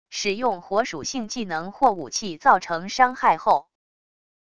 使用火属性技能或武器造成伤害后wav音频